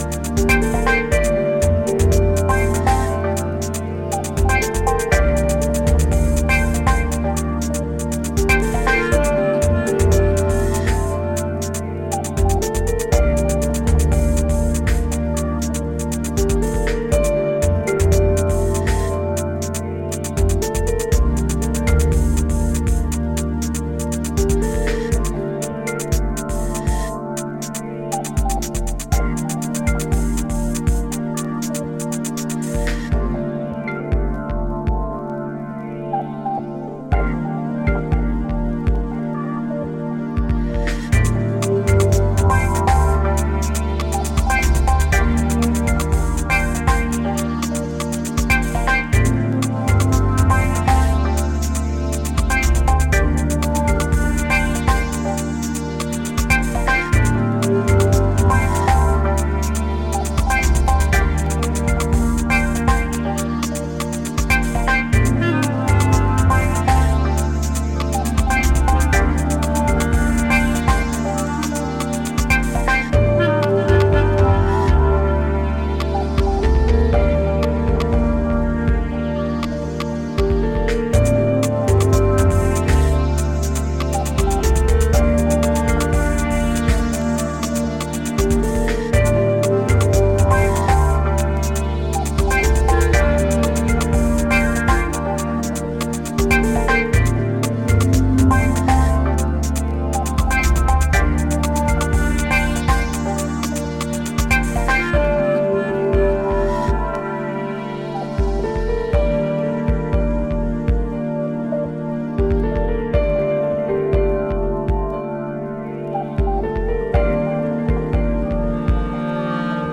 イカツイ低音が太々しく存在を主張したスーパーヘヴィ級グルーヴ